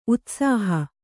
♪ utsāha